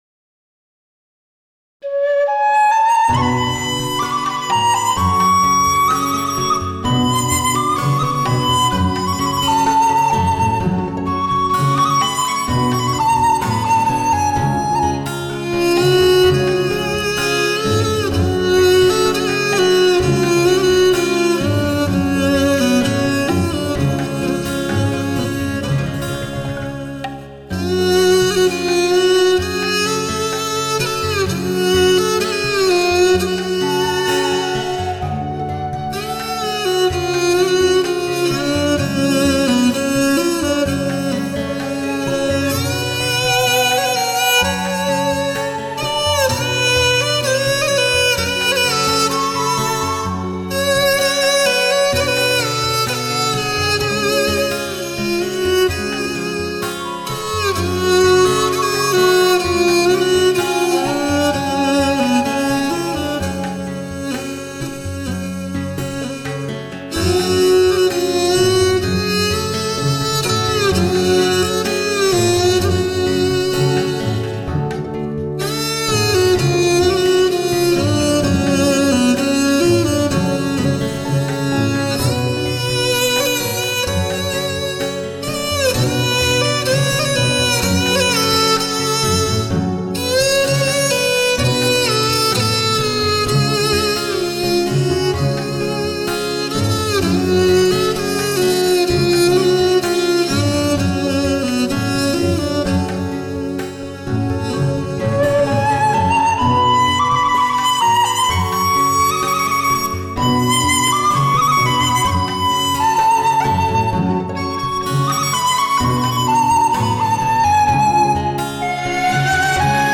音乐类型: 民乐
12首风华绝代的世纪名曲，独树一帜的马头琴音色，
完全感受广阔无边、身临其境的无际音乐宇宙！